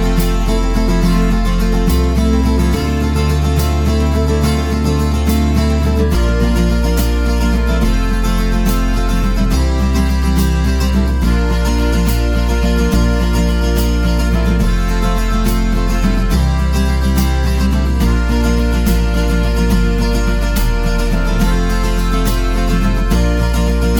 Live Version Pop (1980s) 4:30 Buy £1.50